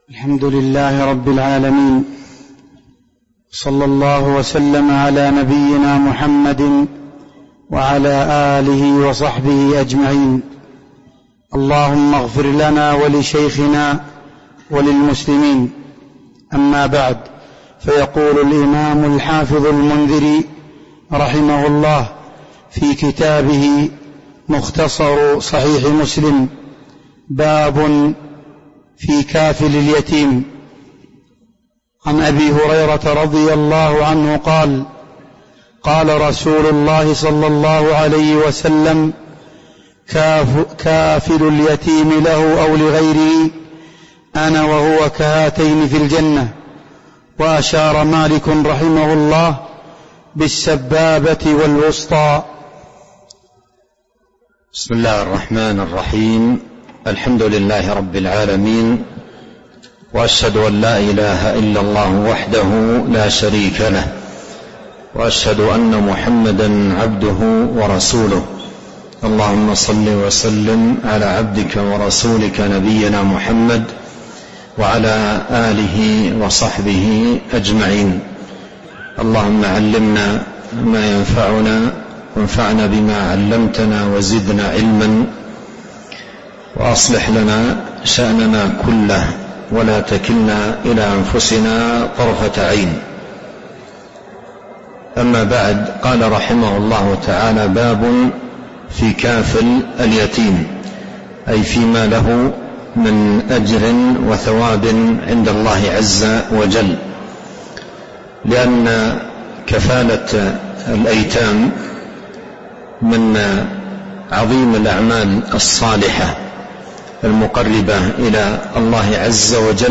تاريخ النشر ٢٤ رمضان ١٤٤٣ هـ المكان: المسجد النبوي الشيخ: فضيلة الشيخ عبد الرزاق بن عبد المحسن البدر فضيلة الشيخ عبد الرزاق بن عبد المحسن البدر باب في كافل اليتيم (04)